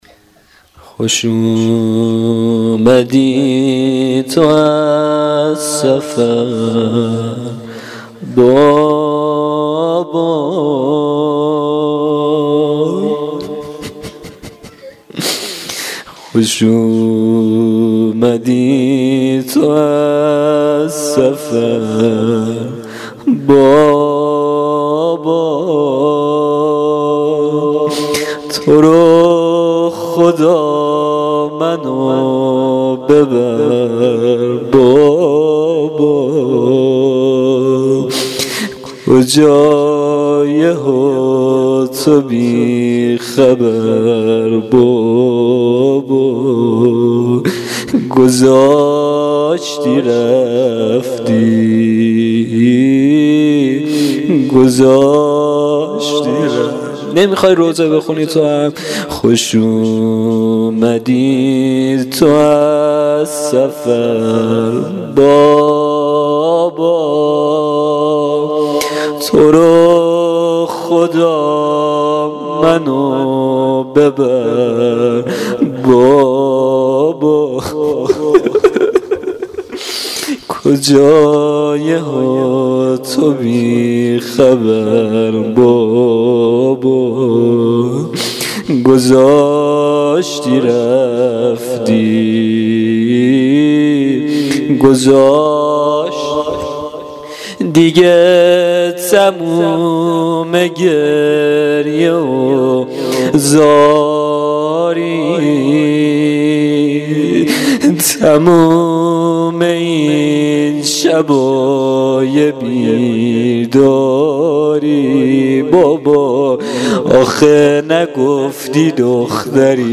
روضه شب سوم محرم الحرام 1395